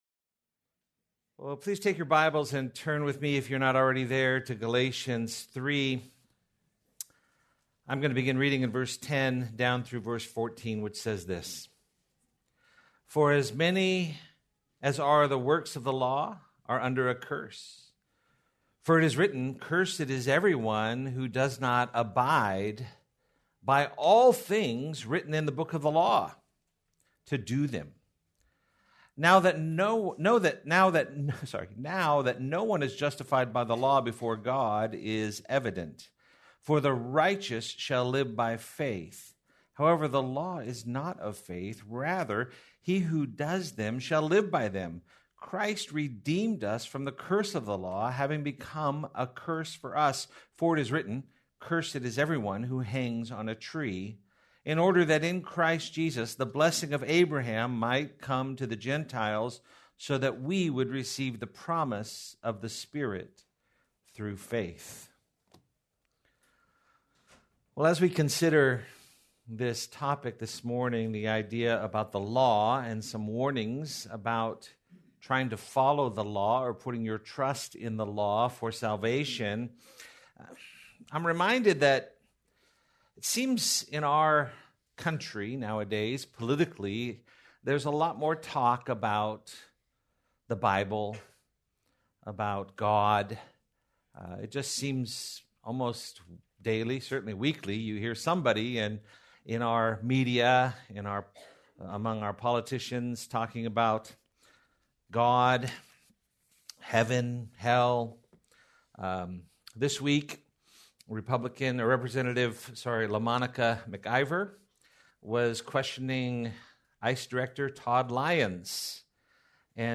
Please note that, due to technical difficulties, this recording skips brief portions of audio.